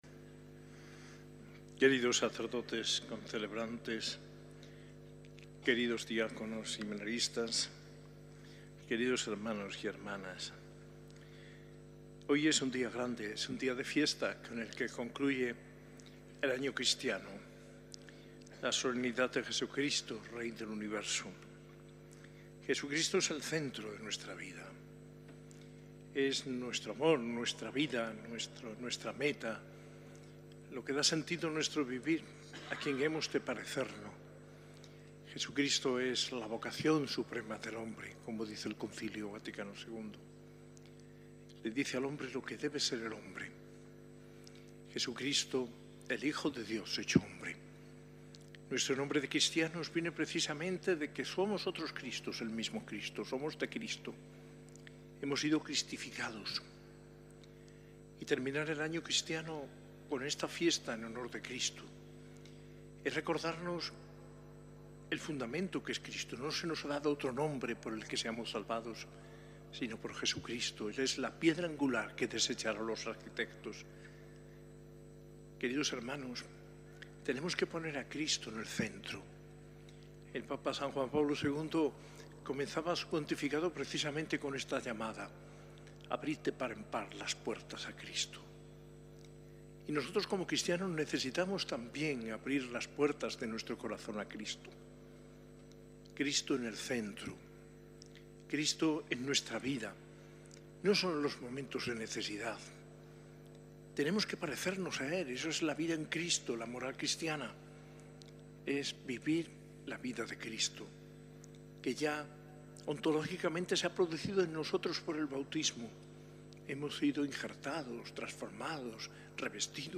Homilía en la Eucaristia celebrada en la S.A.I Catedral en la Solemnidad de Jesucristo, Rey del Universo, el 24 de noviembre de 2024.